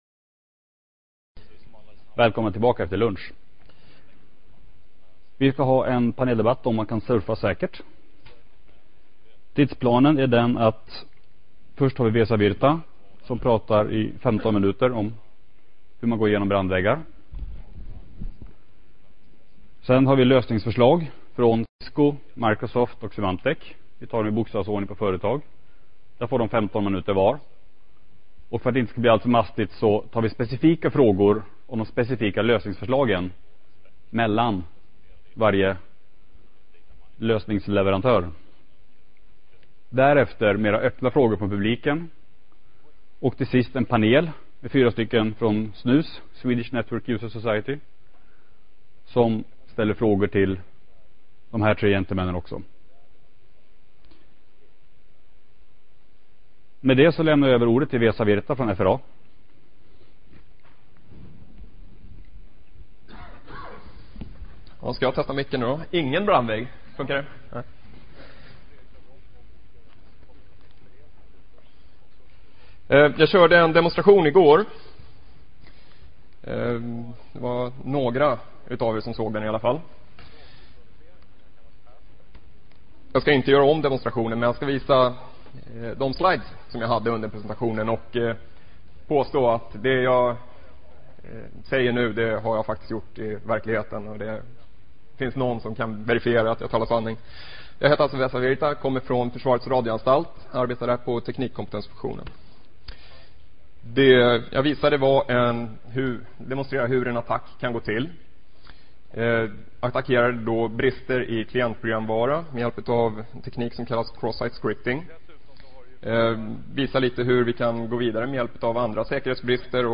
Detta seminarium diskuterar vilka kompletteringar i skyddet som beh�vs f�r att m�ta detta hot.